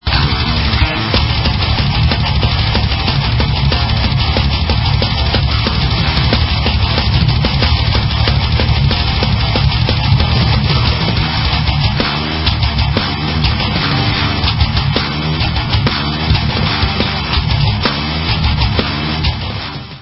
PROG. THRASH METAL